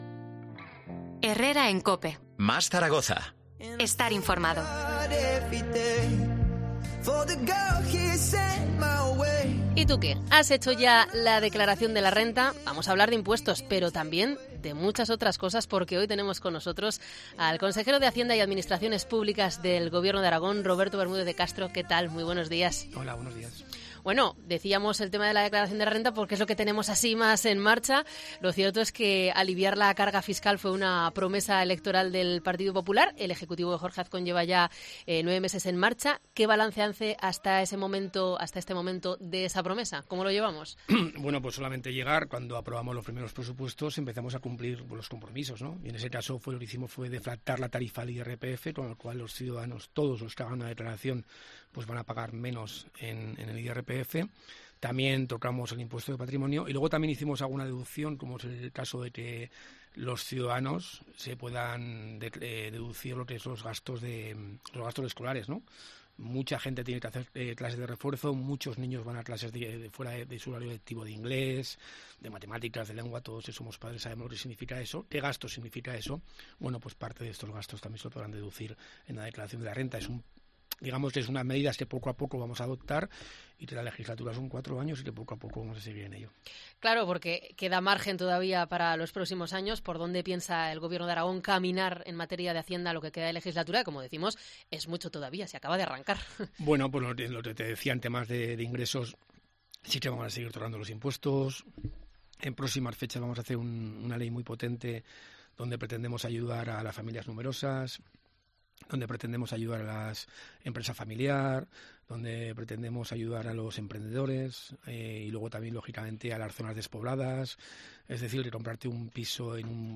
Entrevista al Consejero de Hacienda de la DGA, Roberto Bermúdez de Castro